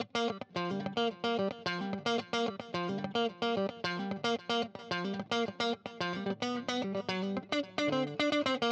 13 Guitar PT 1-4.wav